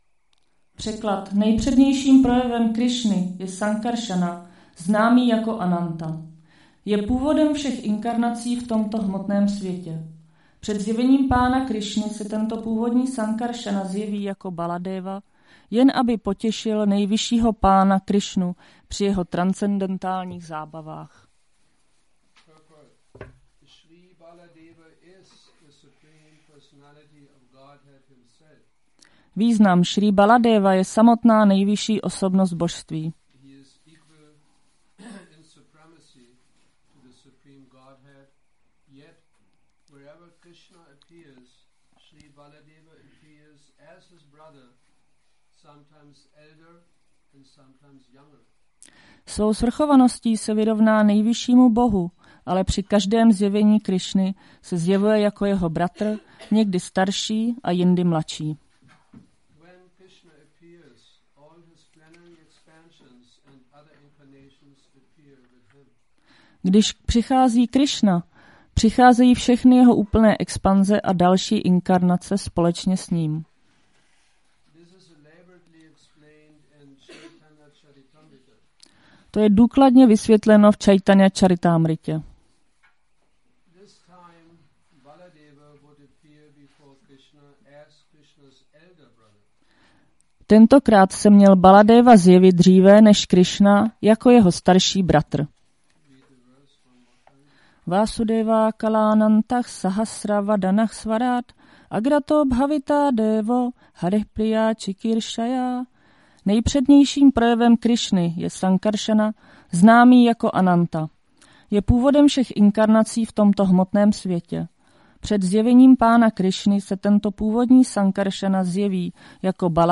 Přednáška SB-10.1.24 – Šrí Šrí Nitái Navadvípačandra mandir